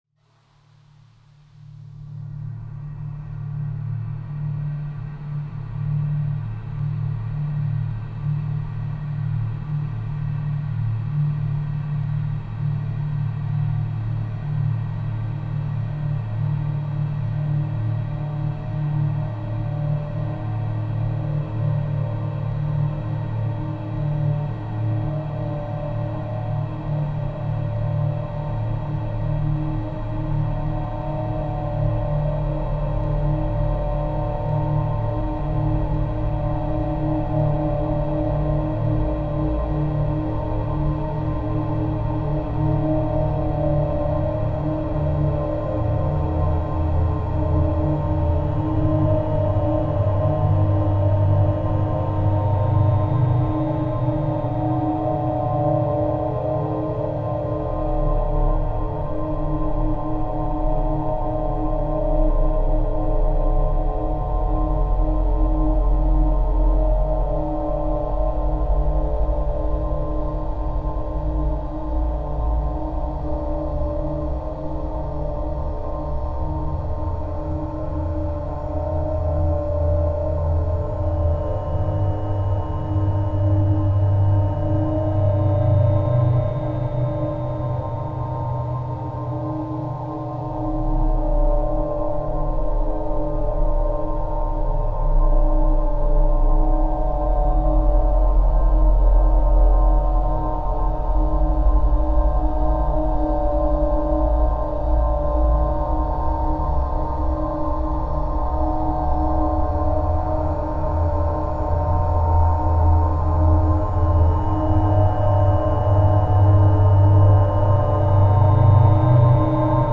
Music / Abstract
Sounds like I'm outside on a cliffside on a windy day.
dark...mysterious...dangerous